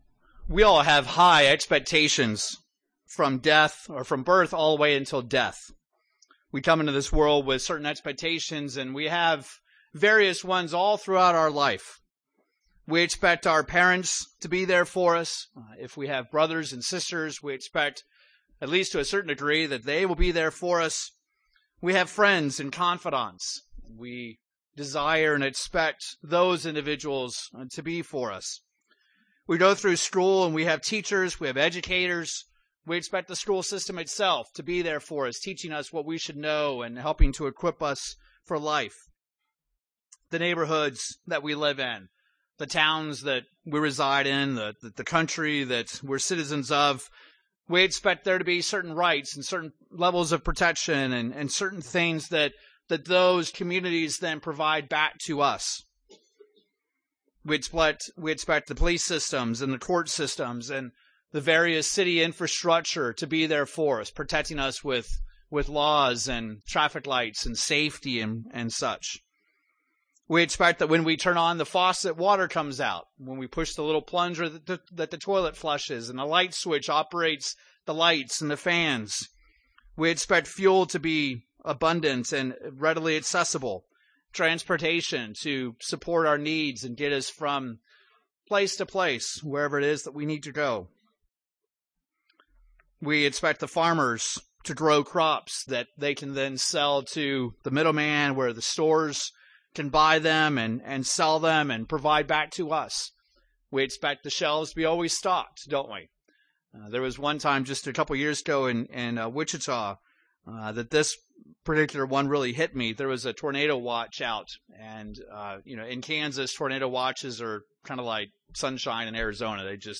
Given in Northwest Arkansas
UCG Sermon Studying the bible?